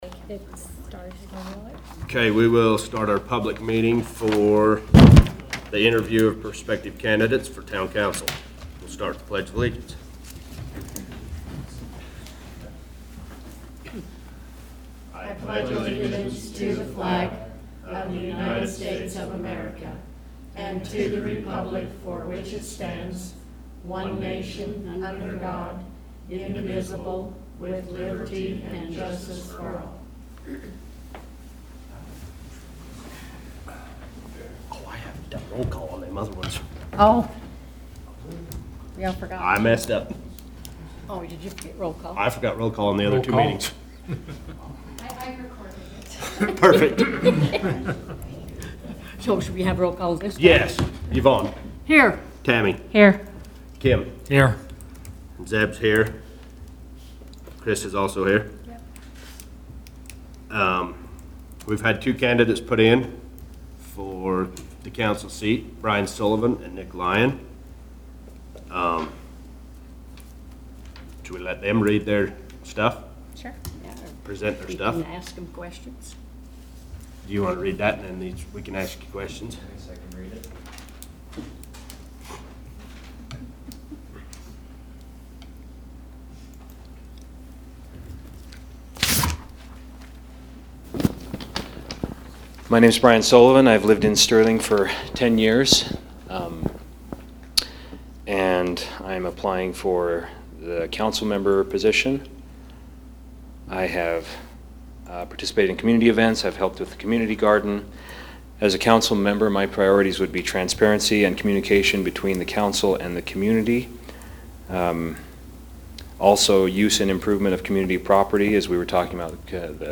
Sterling Town Council Special Public Meeting to interview prospective candidates for Town Council
The interview process will be open for the public to listen and give comments after.